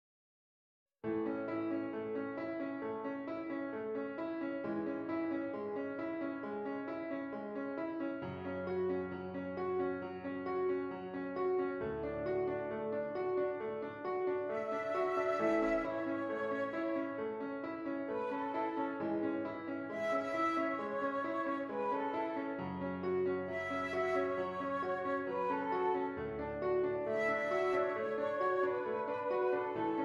Flute Solo with Piano Accompaniment
A Major
Moderately slow